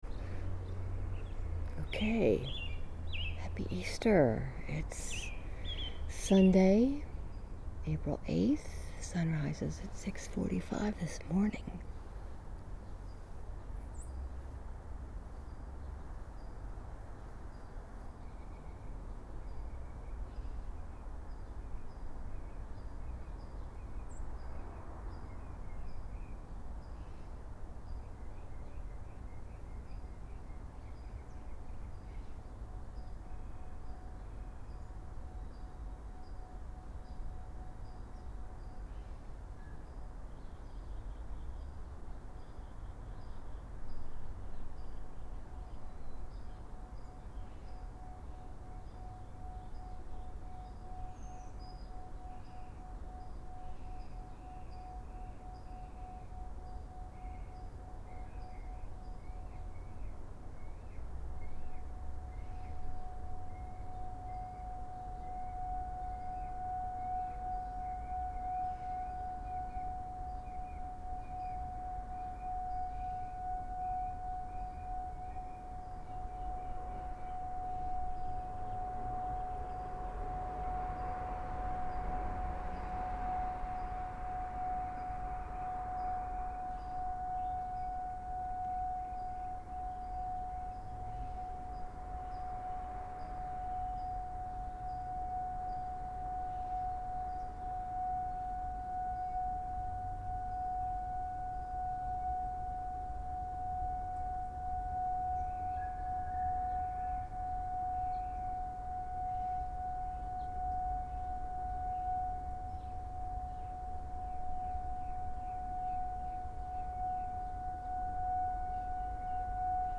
April 8, 2012 Sunrise 6:45 AM